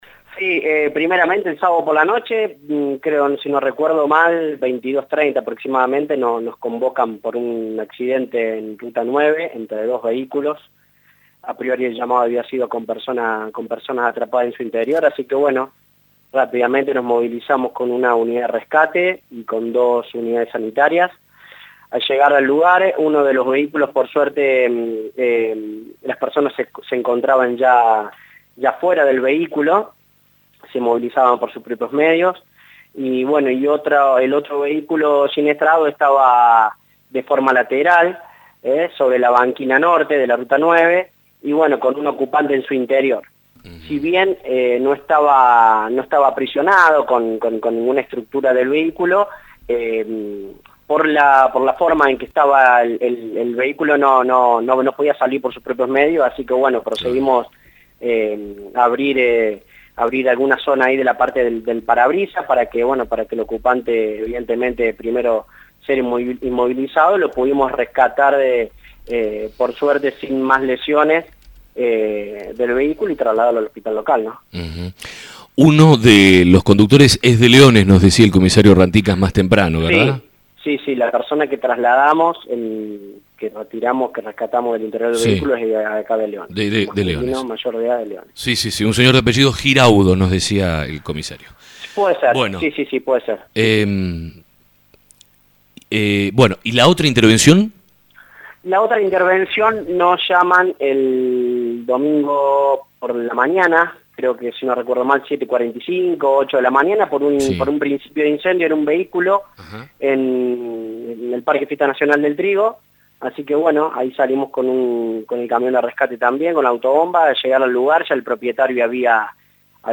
Informe e imágenes en esta nota.